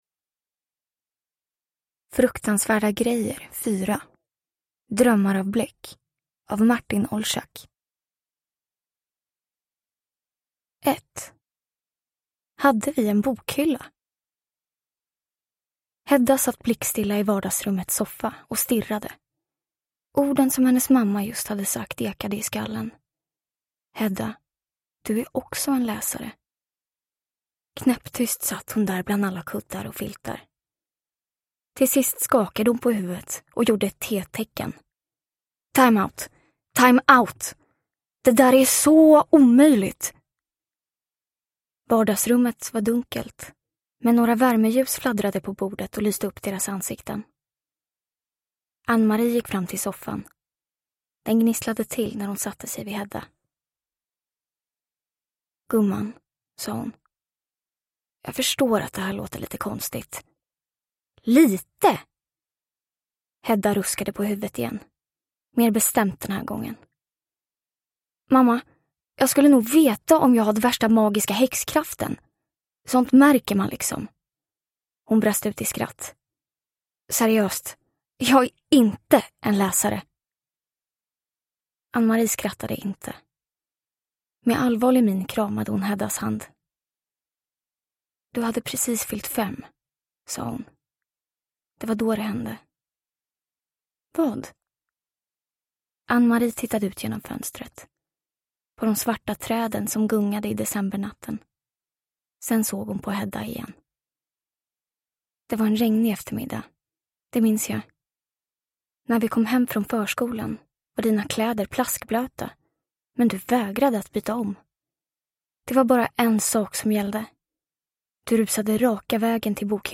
Drömmar av bläck (ljudbok) av Martin Olczak